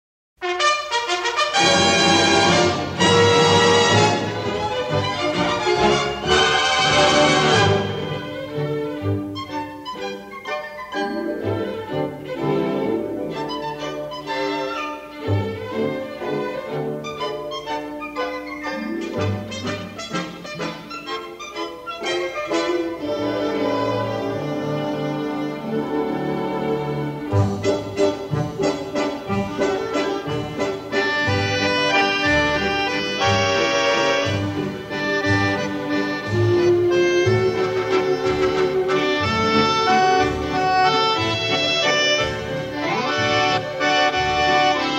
in the best-possible monaural sound.